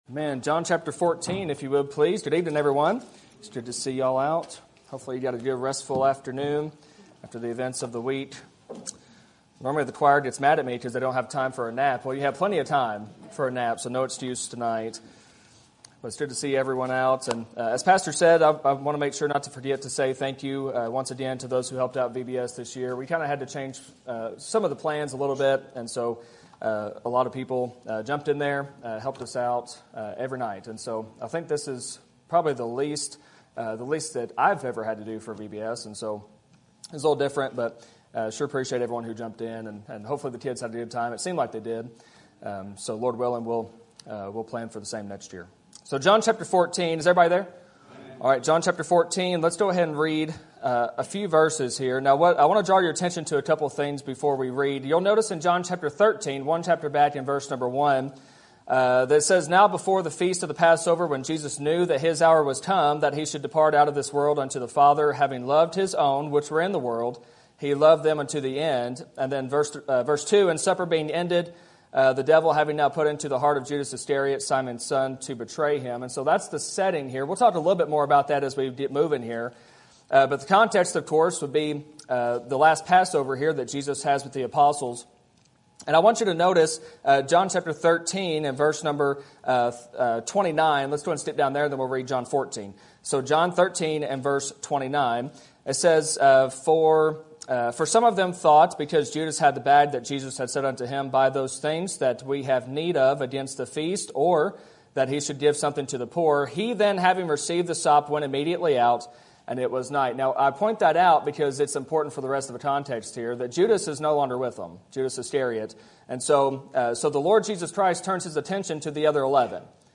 Sermon Topic: General Sermon Type: Service Sermon Audio: Sermon download: Download (21.18 MB) Sermon Tags: John Jesus Passover Love